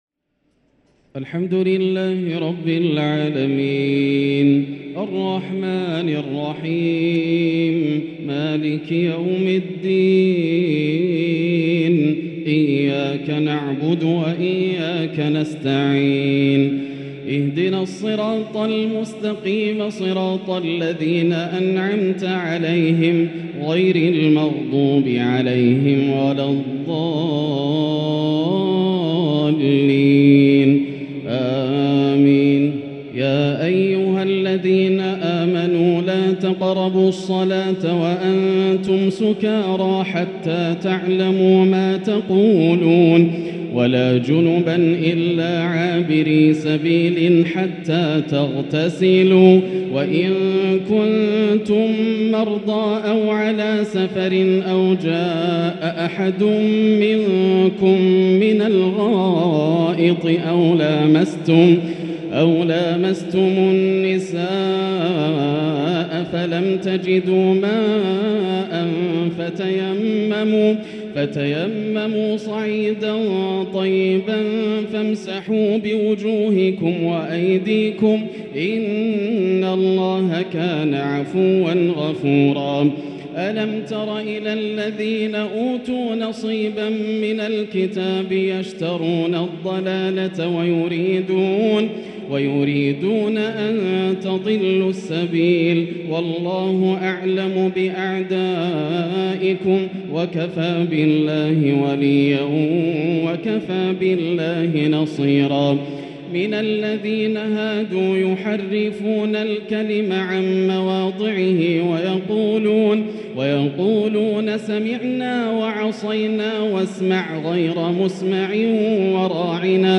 تراويح ليلة 6 رمضان 1444هـ من سورة النساء {43-87} > الليالي الكاملة > رمضان 1444هـ > التراويح - تلاوات ياسر الدوسري